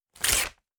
撕纸.wav